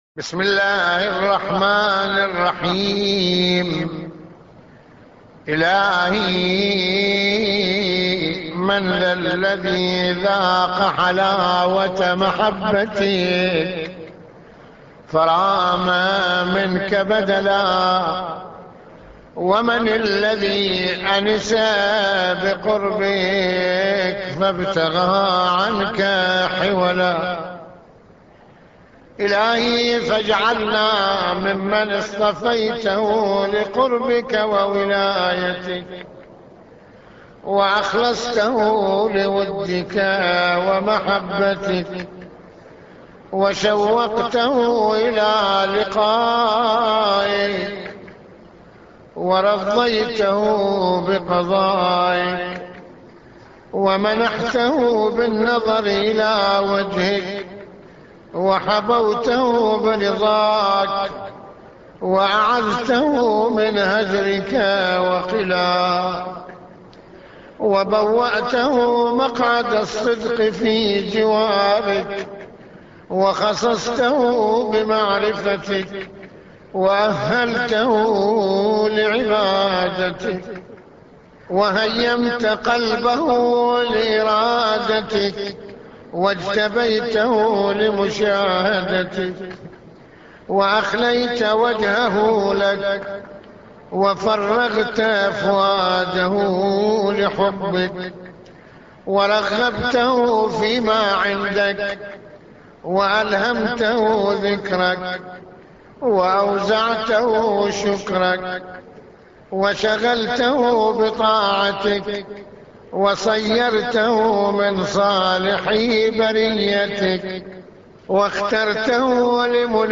مناجاة
- استمع للدعاء بصوت سماحته